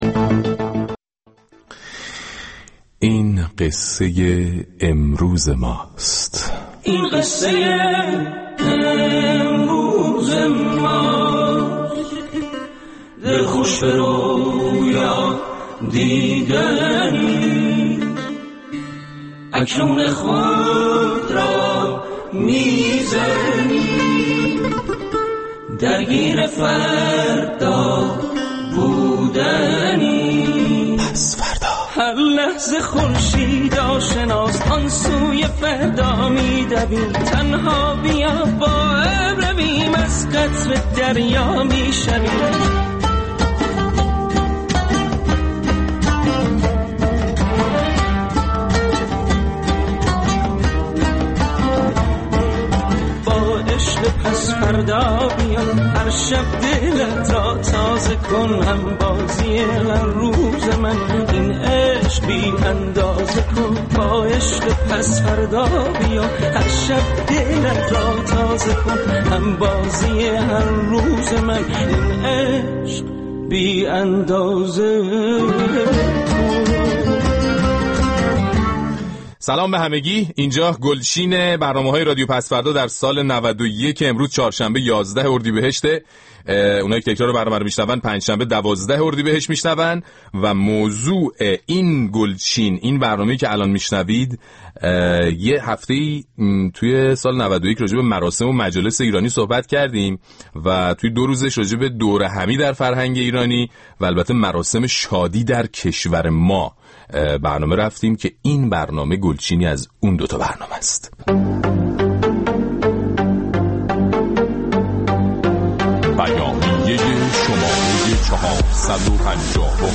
رادیو پس‌فردا برنامه‌ای است در رادیو فردا که از شنبه تا چهارشنبه به مدت یک ساعت از ۲۱:۰۰ تا ۲۲:۰۰ شب به وقت ایران با اجرای فرشید منافی با زبان طنز آزاد به مسائل سیاسی، اجتماعی، اقتصادی، هنری و حتی ورزشی روز ایران و جهان می‌پردازد.